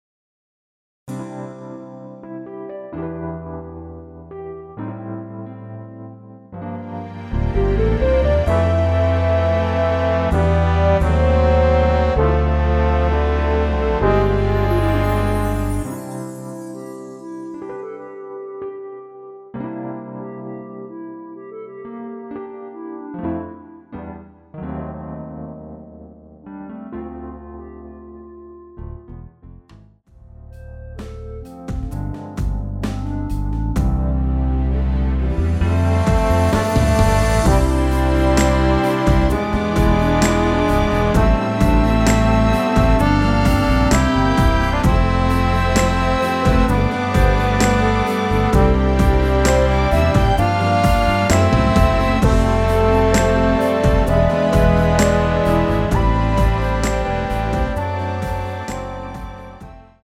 원키에서(-3)내린 멜로디 포함된 MR입니다.(미리듣기 참조)
앞부분30초, 뒷부분30초씩 편집해서 올려 드리고 있습니다.
(멜로디 MR)은 가이드 멜로디가 포함된 MR 입니다.